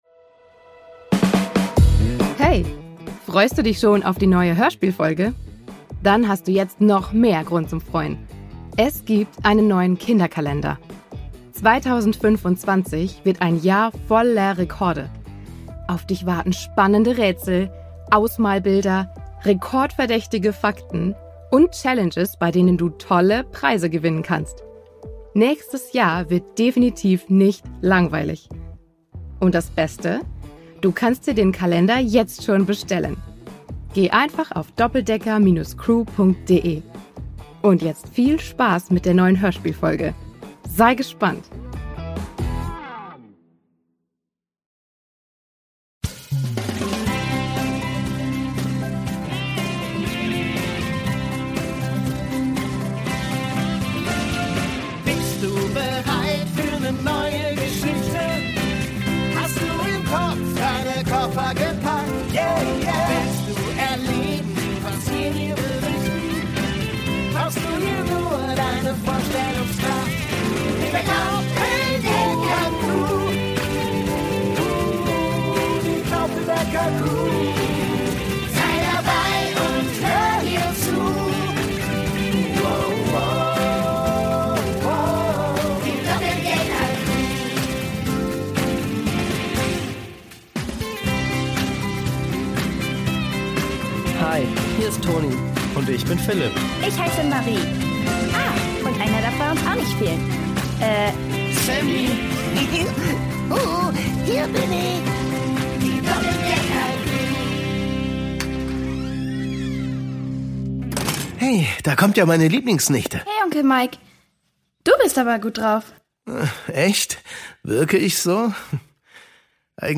Island 1: Brodeln aus dem Inneren (1/3) | Die Doppeldecker Crew | Hörspiel für Kinder (Hörbuch) ~ Die Doppeldecker Crew | Hörspiel für Kinder (Hörbuch) Podcast